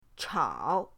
chao3.mp3